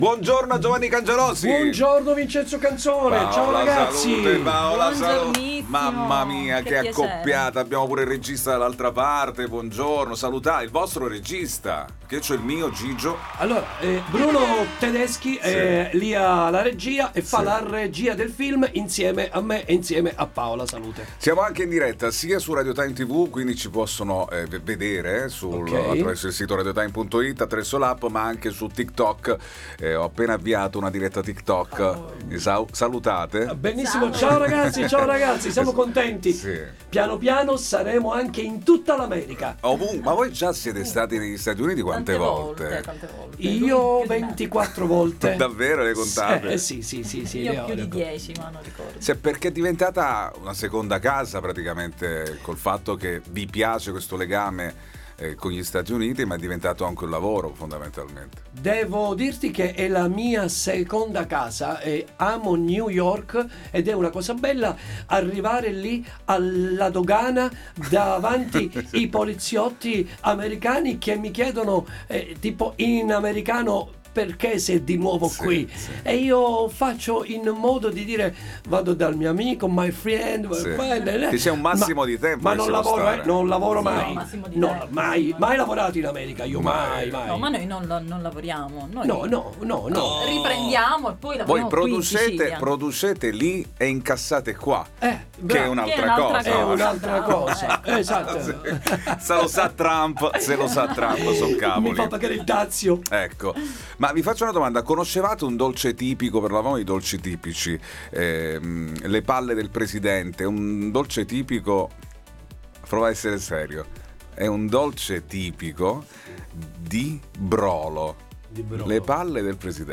All Inclusive Interviste